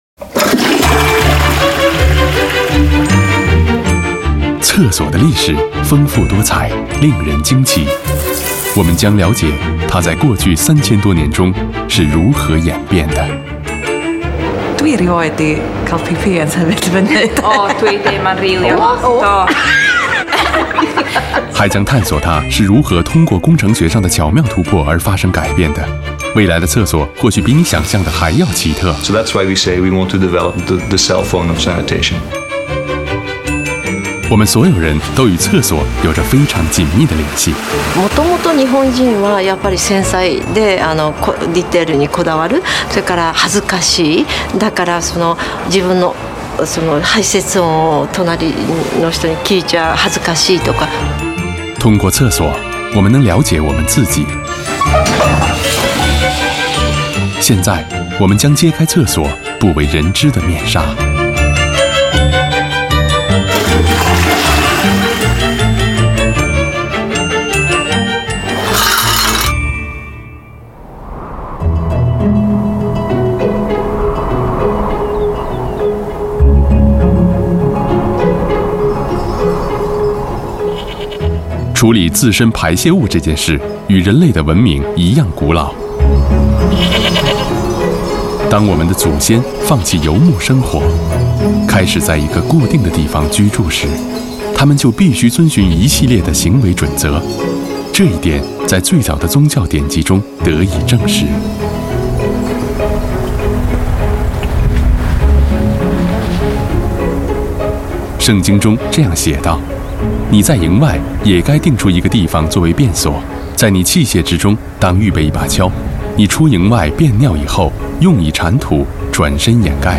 国语青年大气浑厚磁性 、沉稳 、积极向上 、时尚活力 、男广告 、600元/条男11 国语 男声 广告-奔驰汽车广告奔驰SUV-B版本-01 大气浑厚磁性|沉稳|积极向上|时尚活力